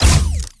Added more sound effects.
GUNAuto_Plasmid Machinegun C Single_06_SFRMS_SCIWPNS.wav